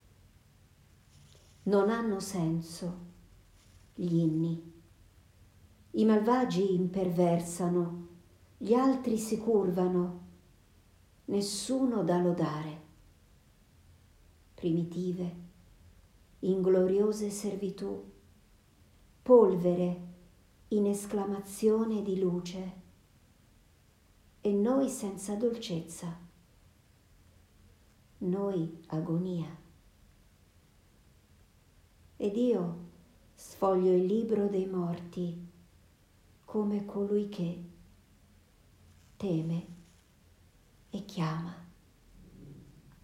dal titolo Come colui che teme e chiama Tratta da questa pubblicazione, presento e leggo nell’ audio, la poesia che racchiude il verso che dà il titolo all’ opera.